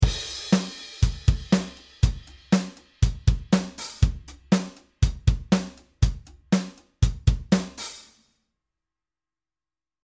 ezd3_main.mp3